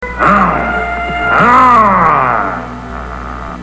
Grrrrr - Frankenstein